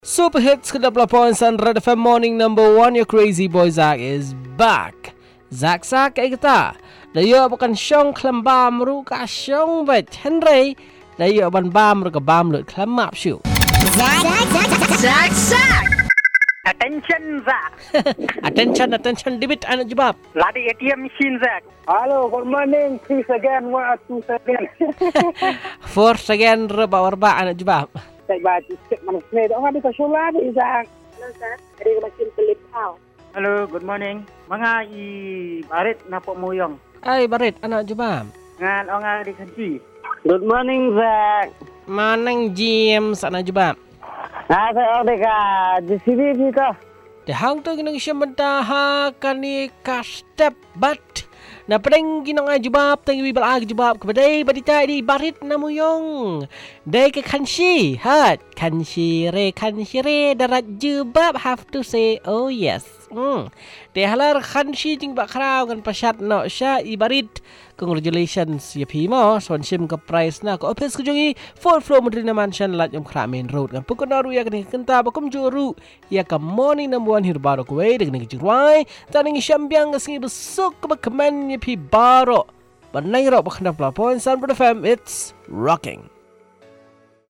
Calls and result